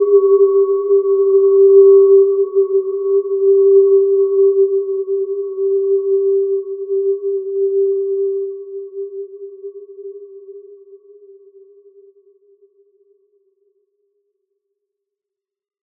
Gentle-Metallic-3-G4-mf.wav